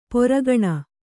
♪ poragaṇa